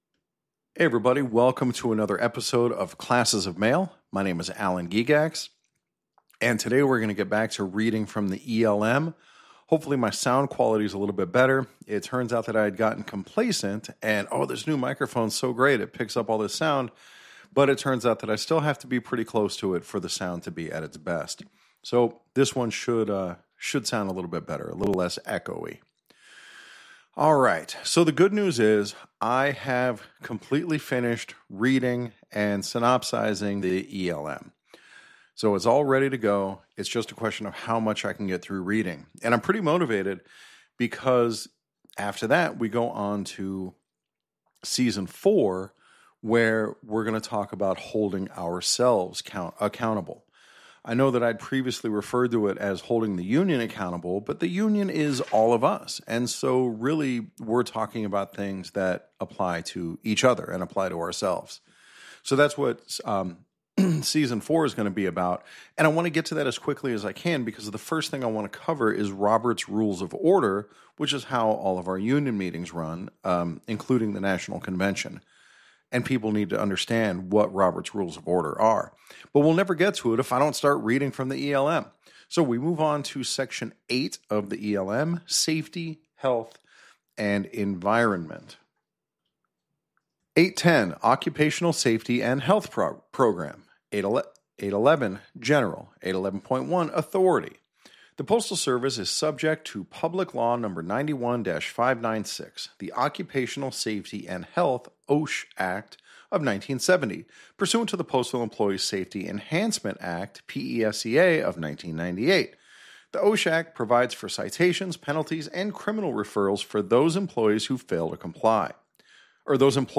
There are a couple of brief pauses, but I managed to take out the big chunk where my microphone was muted.